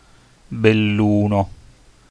Belluno (Italian pronunciation: [belˈluːno]